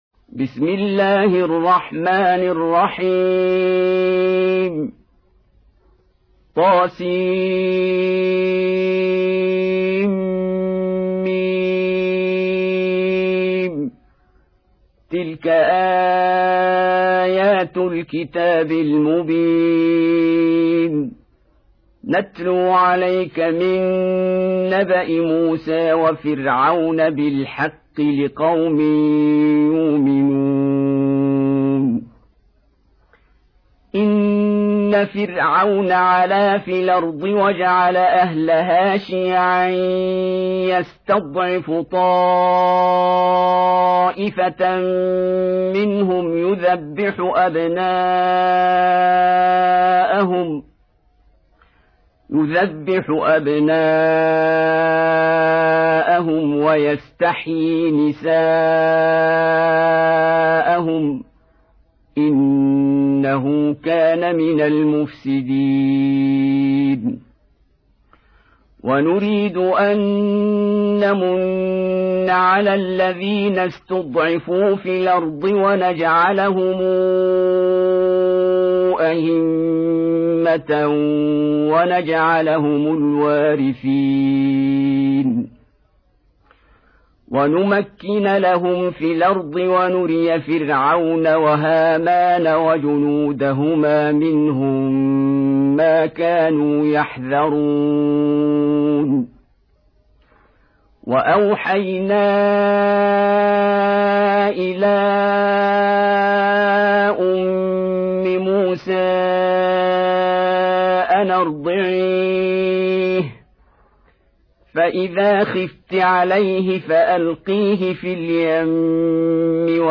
28. Surah Al-Qasas سورة القصص Audio Quran Tarteel Recitation
Surah Repeating تكرار السورة Download Surah حمّل السورة Reciting Murattalah Audio for 28.